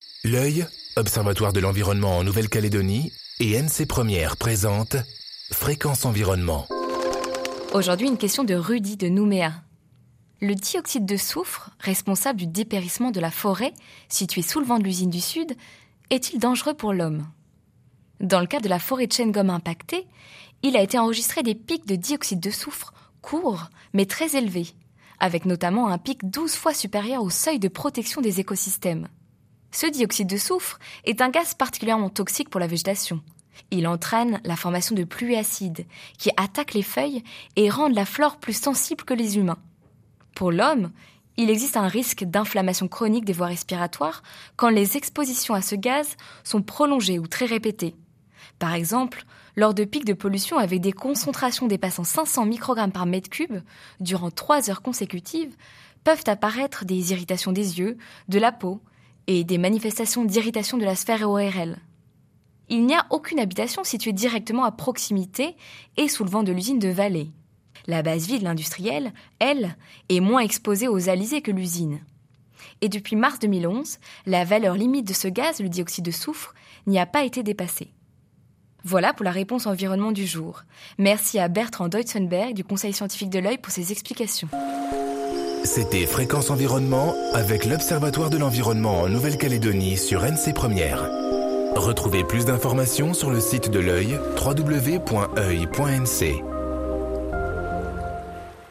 diffusée en janvier 2014 sur NC 1ère